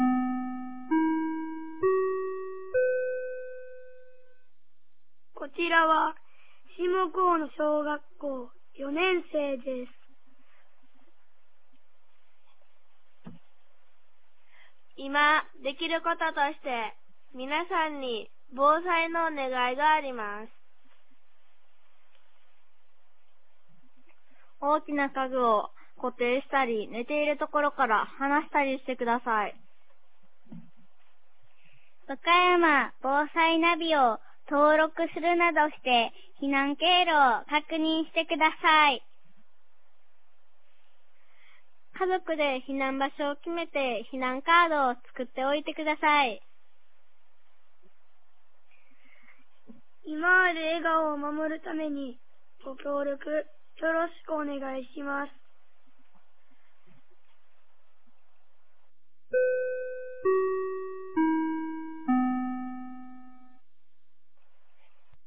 2024年02月05日 15時31分に、紀美野町より全地区へ放送がありました。